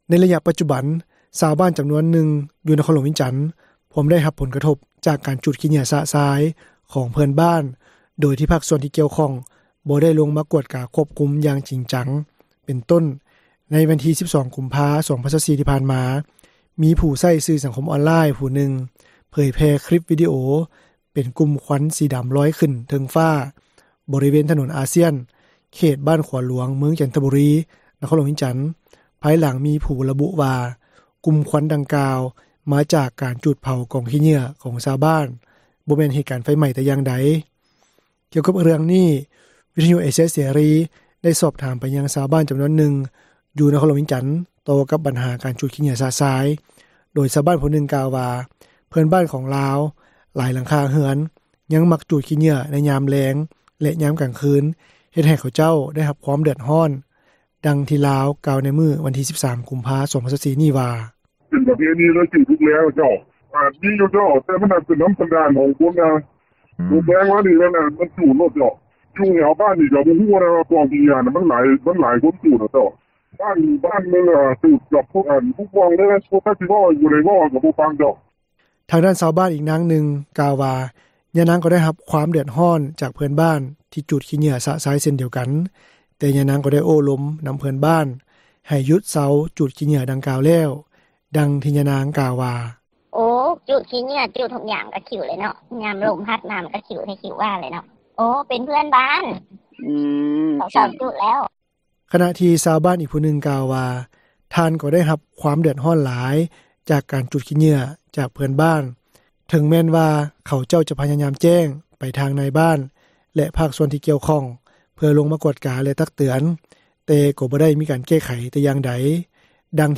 ກ່ຽວກັບເຣື່ອງນີ້, ວິທຍຸເອເຊັຽເສຣີ ໄດ້ສອບຖາມໄປຍັງຊາວບ້ານ ຈຳນວນນຶ່ງ ຢູ່ນະຄອນຫຼວງວຽງຈັນ ຕໍ່ກັບບັນຫາ ການຈູດຂີ້ເຫຍື້ອຊະຊາຍ, ໂດຍຊາວບ້ານ ຜູ້ນຶ່ງ ກ່າວວ່າ ເພື່ອນບ້ານຂອງລາວ ຫຼາຍຫຼັງຄາເຮືອນ ຍັງມັກຈູດຂີ້ເຫຍື້ອ ໃນຍາມແລງ ແລະ ຍາມກາງຄືນ ເຮັດໃຫ້ເຂົາເຈົ້າ ໄດ້ຮັບຄວາມເດືອດຮ້ອນ.